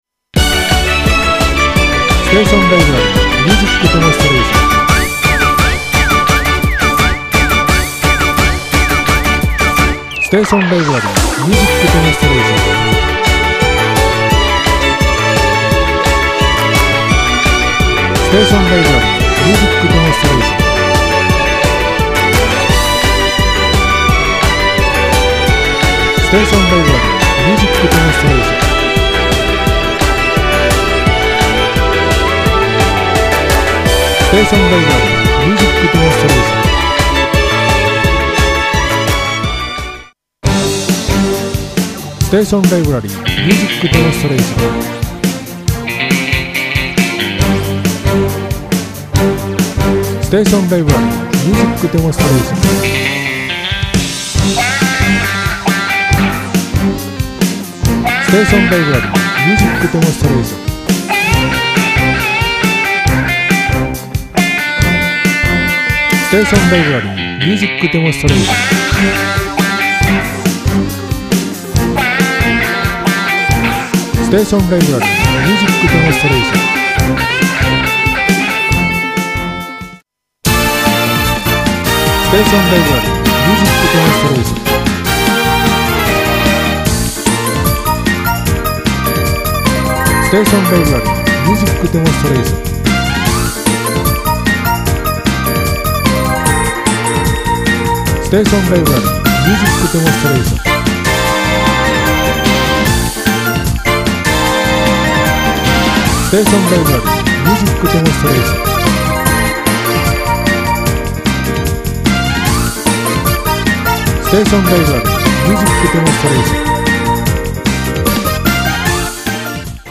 躍動感に満ちた元気の出る音楽を中心に取り揃えました。うきうきわくわく楽しい音楽で一杯です。ＨＯＴな音楽をお試し下さい。